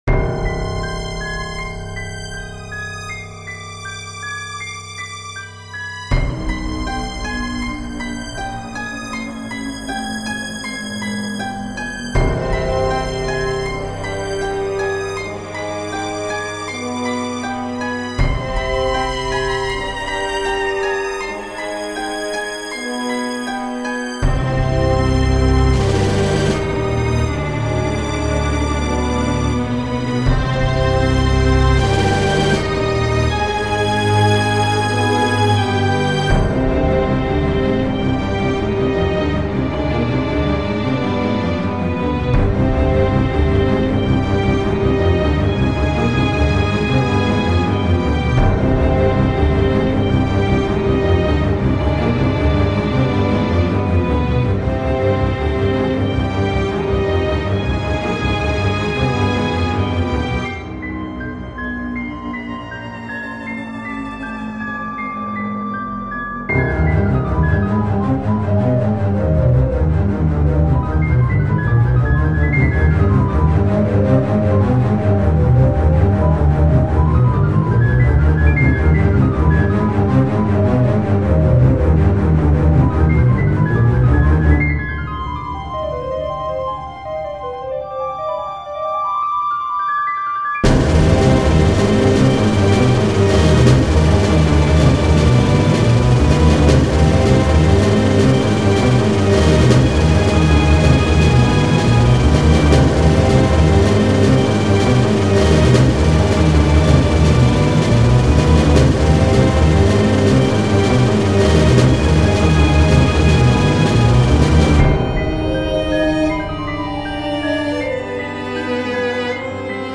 You won't hear very much brightness, but.